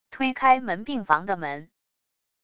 推开门病房的门wav音频